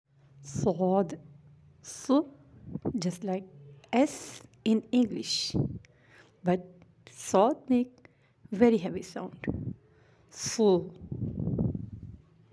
But it make a very heavy sound as Ṣād ص is one of the Heavy letters of Arabic alphabets.
How to pronounce Saad ص
Letter sad ص is pronounced when tip of tongue is placed on the plate of two lower incisors.